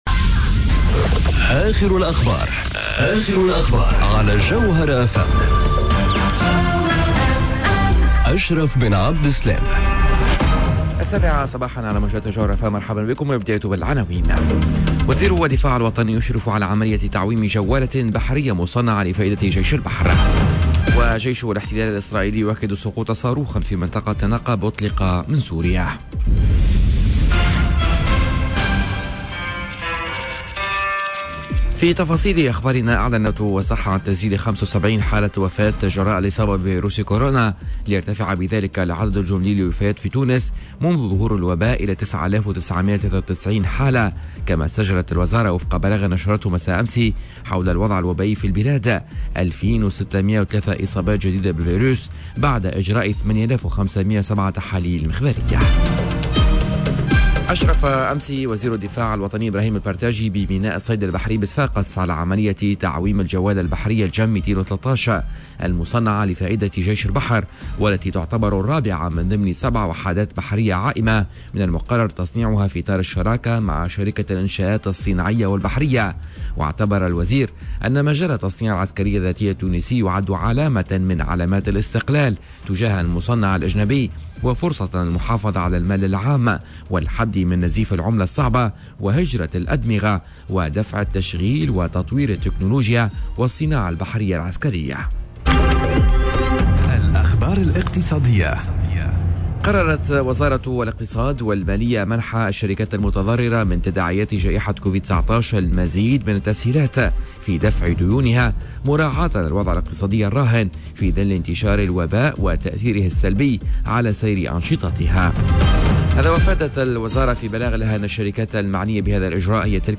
نشرة أخبار السابعة صباحا ليوم الخميس 22 أفريل 2021